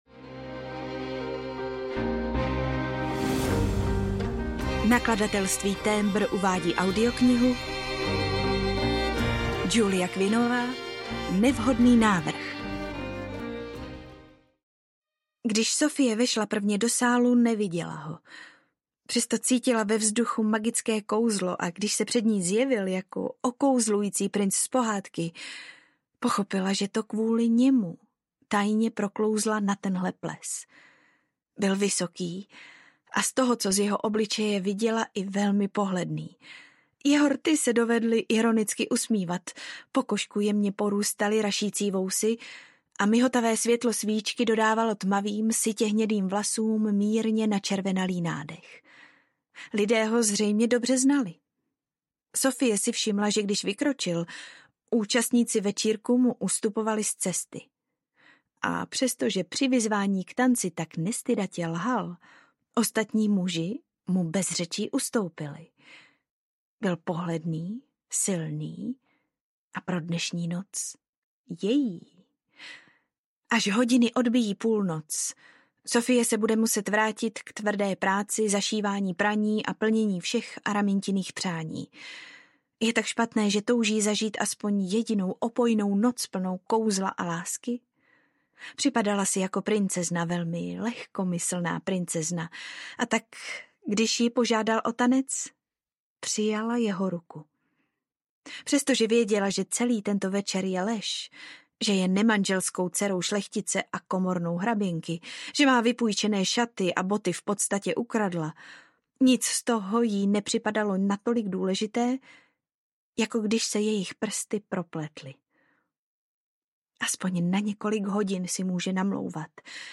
Nevhodný návrh audiokniha
Ukázka z knihy